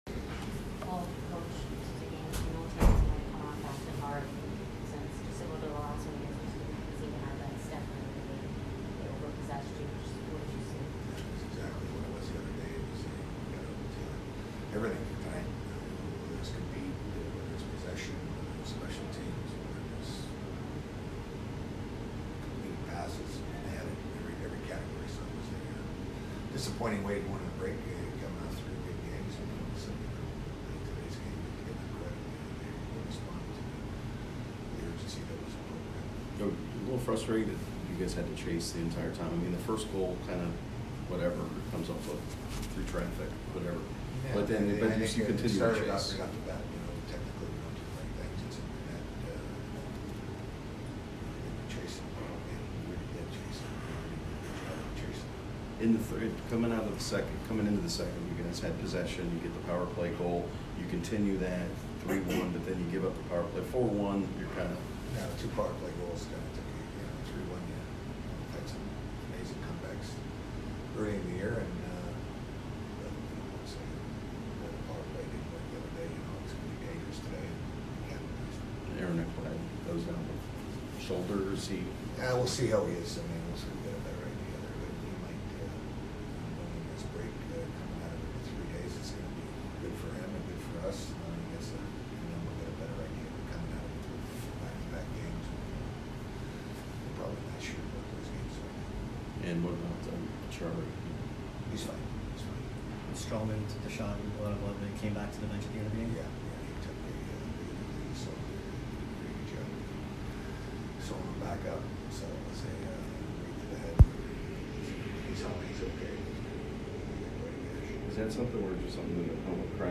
Quenneville post-game 12/23